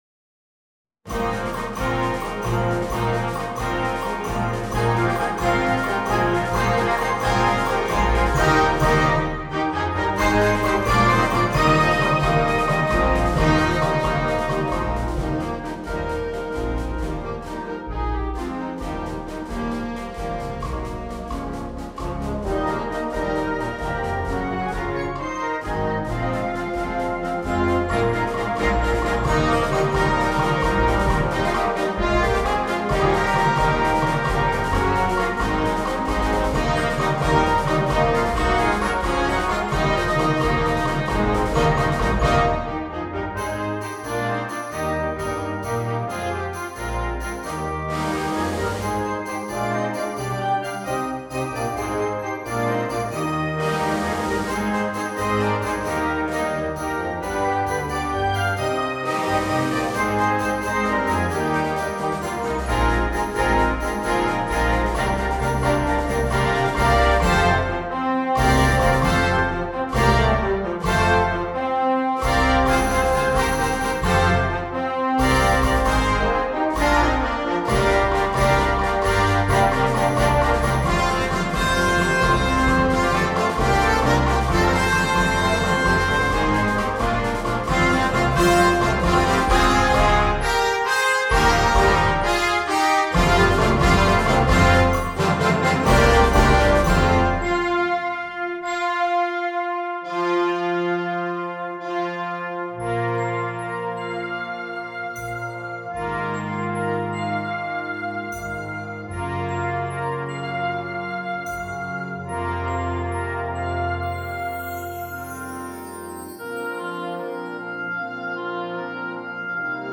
Gattung: Konzertwerk